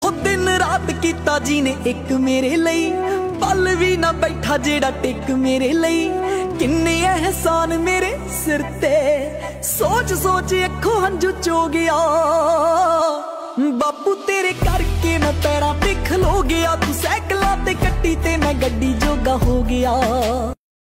A soulful Punjabi ringtone dedicated to fatherly love.